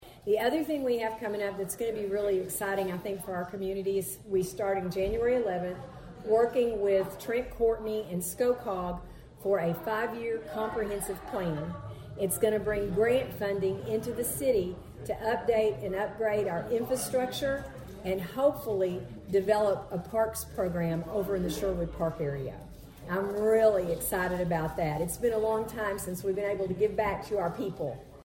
Thayer Mayor Donna Martin speaks at Rotary on Wednesday